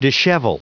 Prononciation du mot dishevel en anglais (fichier audio)
Prononciation du mot : dishevel